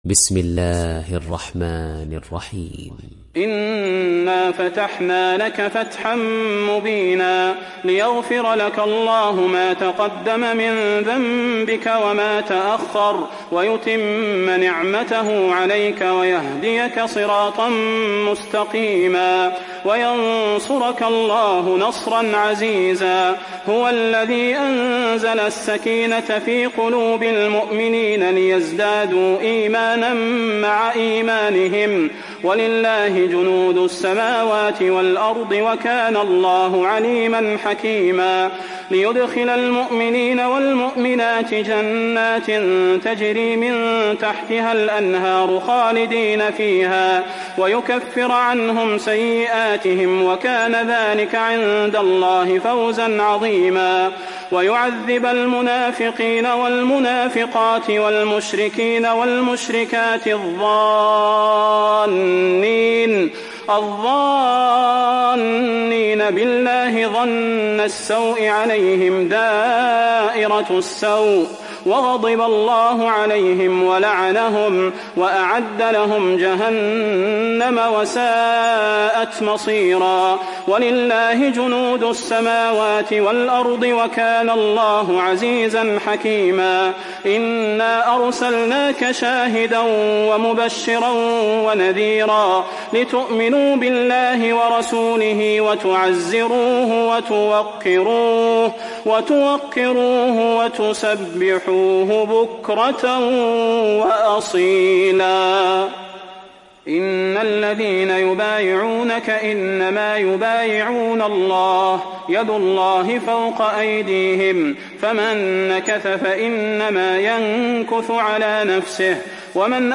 Surat Al Fath Download mp3 Salah Al Budair Riwayat Hafs dari Asim, Download Quran dan mendengarkan mp3 tautan langsung penuh